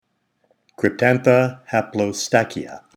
Pronunciation/Pronunciación:
Cryp-tán-tha ha-plo-stà-chy-a